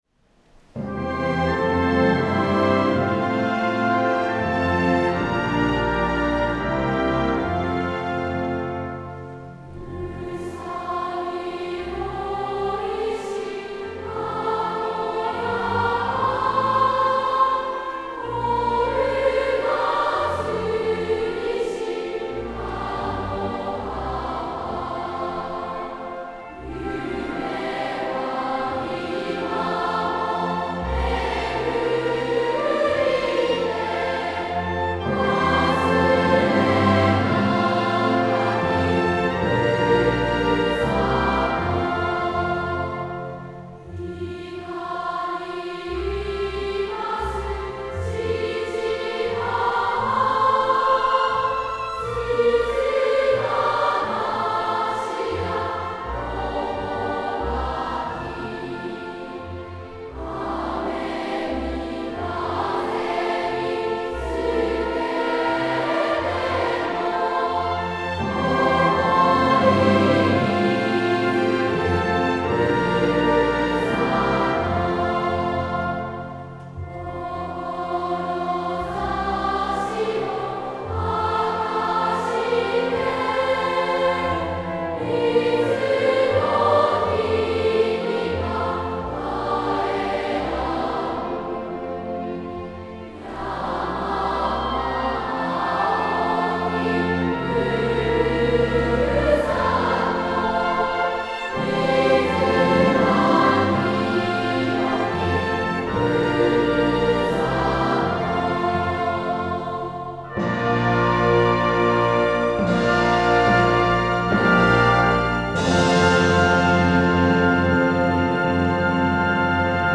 第一部　同声合唱